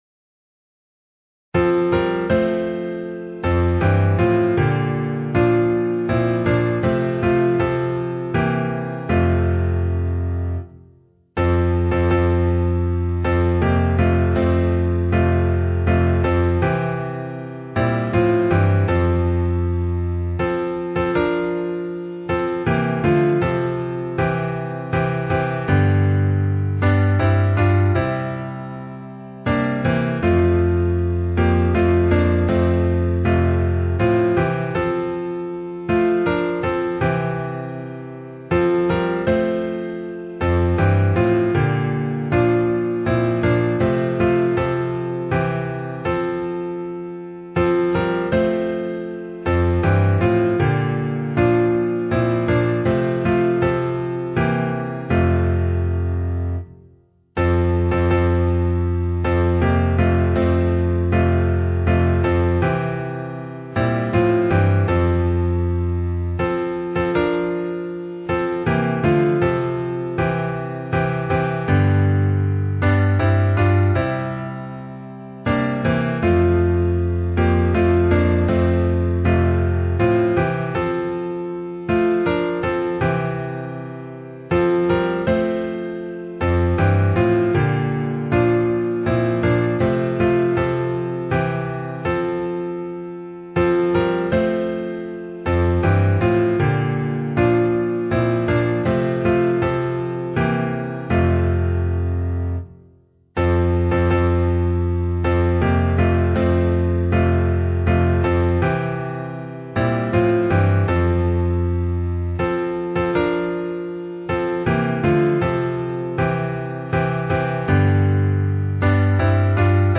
Revelation 7:17 Key: G Meter